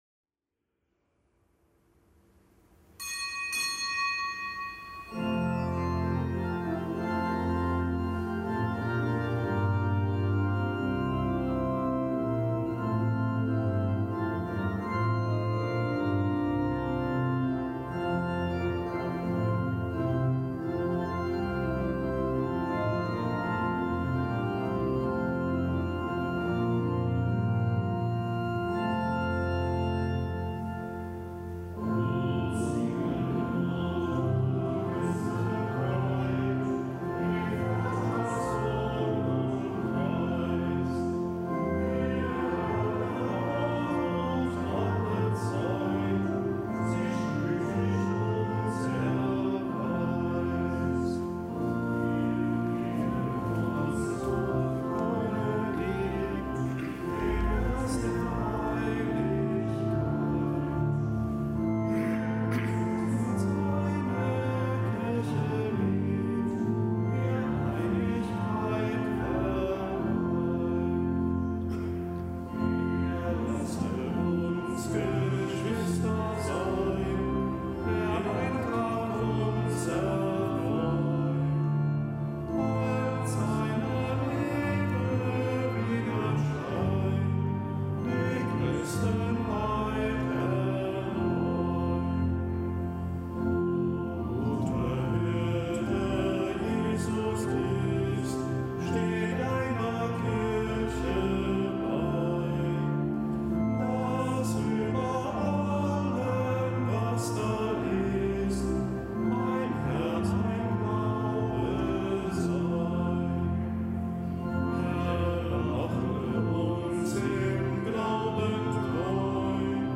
Kapitelsmesse am Gedenktag des Heiligen Kunibert
Kapitelsmesse aus dem Kölner Dom am Gedenktag des Heiligen Kunibert, Bischof von Köln (DK)